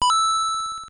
Play, download and share SMB3 Coin original sound button!!!!